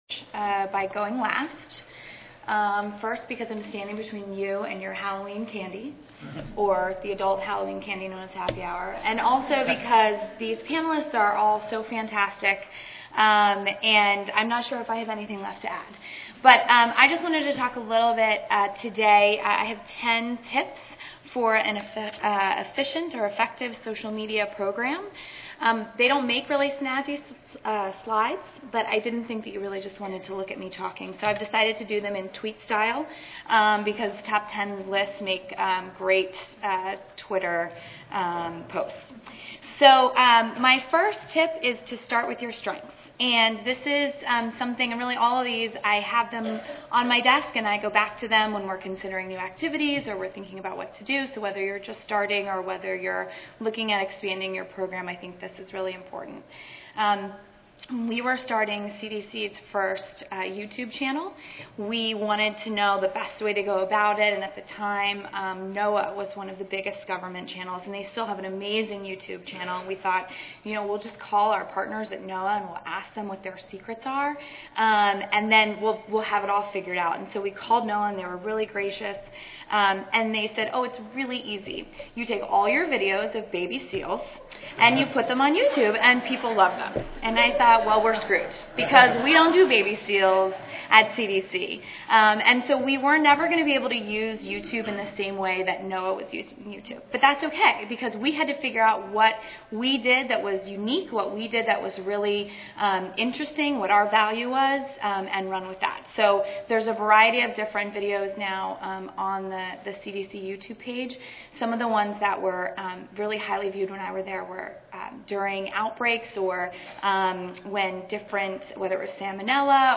3417.0 The Role of Social Media in Public Health Monday, October 31, 2011: 4:30 PM Oral Ready to take your communications strategy to the next level? This session is designed to help enhance visibility of public health issues by leveraging social media tools within a communications campaign. The panel will share best practices on how to engage key health bloggers and build a dialogue with a wide audience.